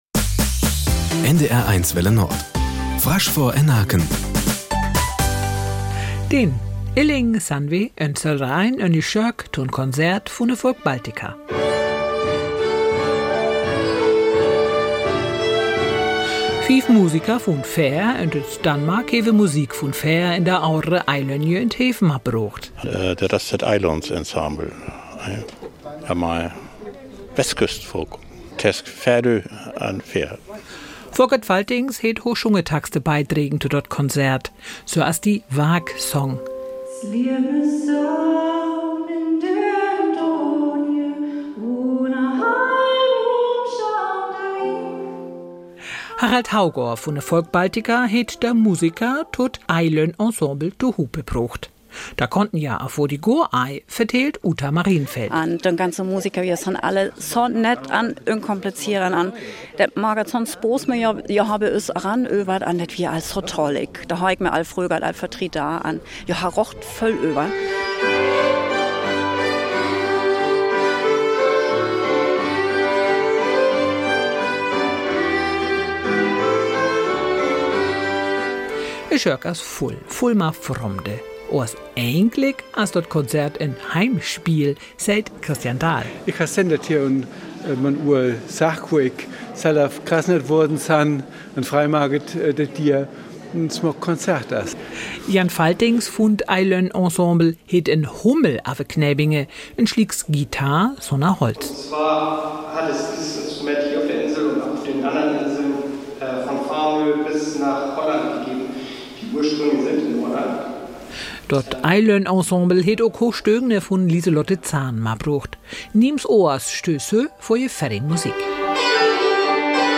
Nachrichten 12:00 Uhr - 09.05.2024